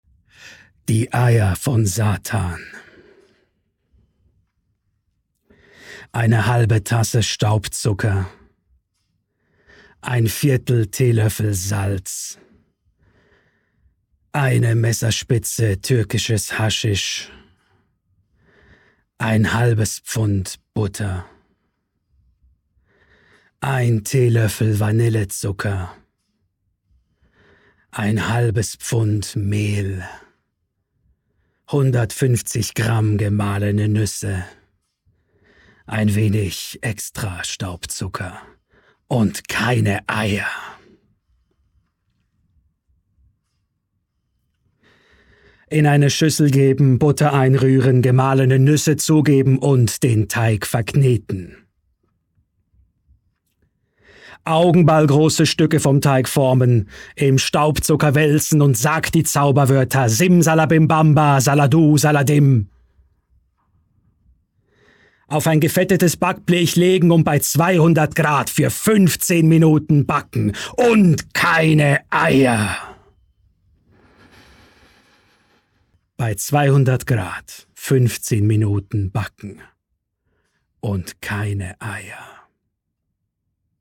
Hörspiel Hochdeutsch (CH) Hörprobe 01